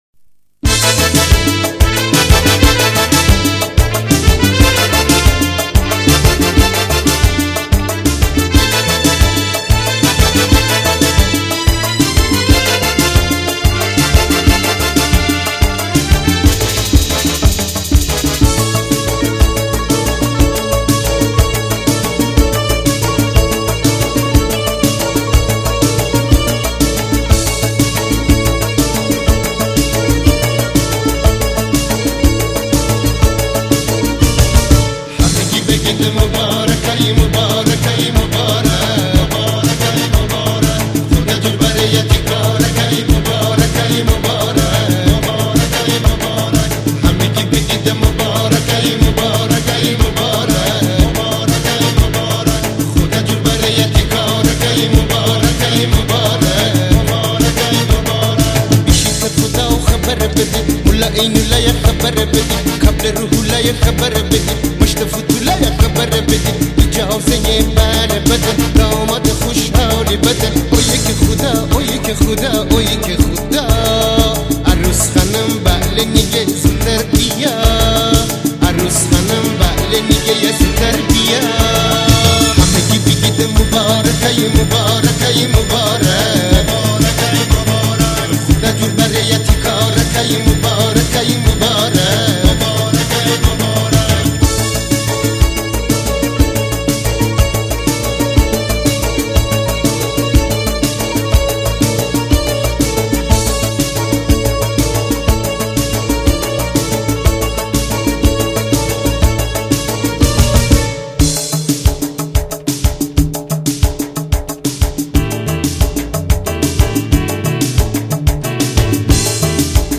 دانلود آهنگ شمالی گیلکی تند شاد برای عروسی و رقص